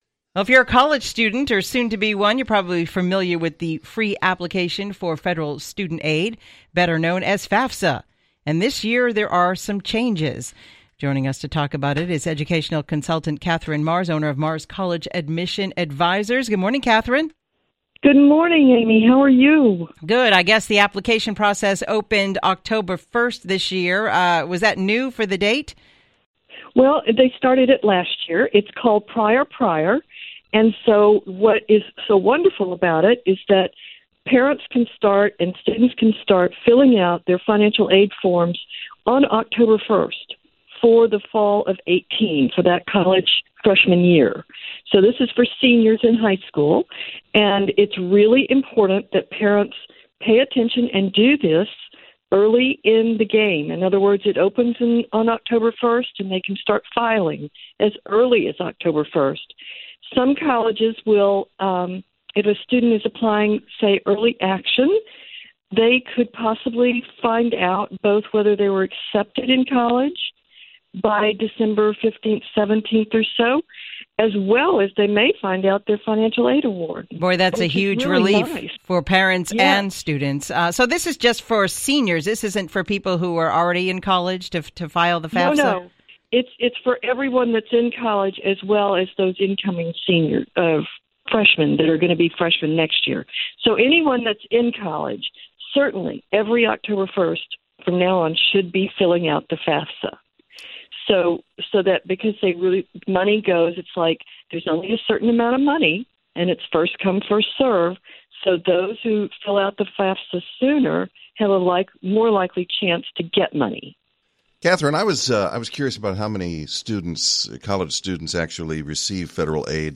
Educational Consultant